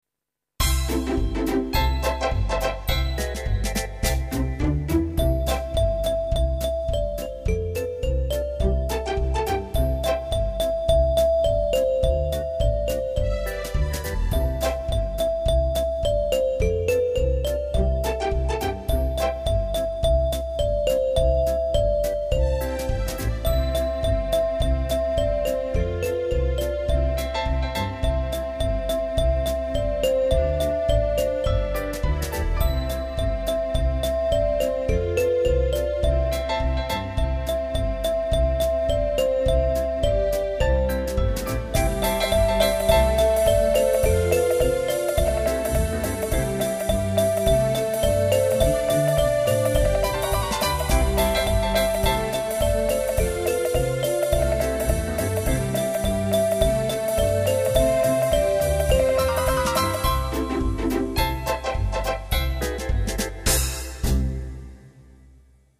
Arrangiamenti didattici di brani d'autore